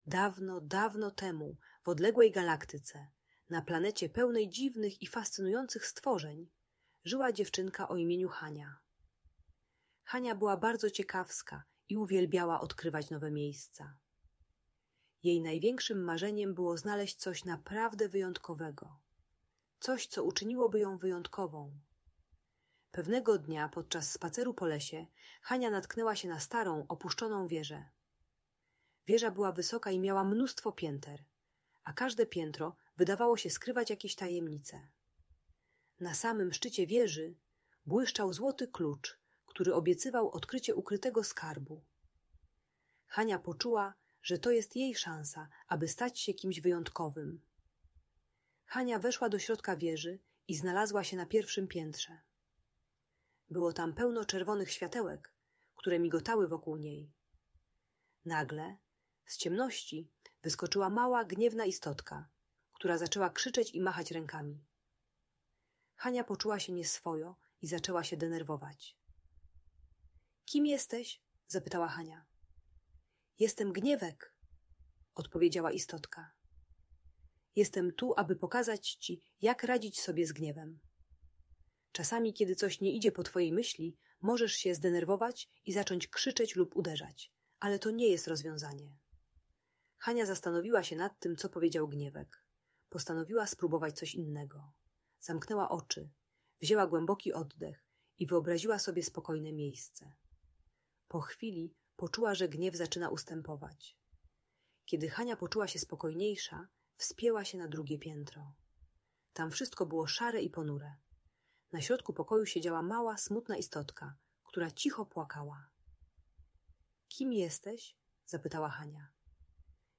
Opowieść o Hani i wieży emocji - Audiobajka dla dzieci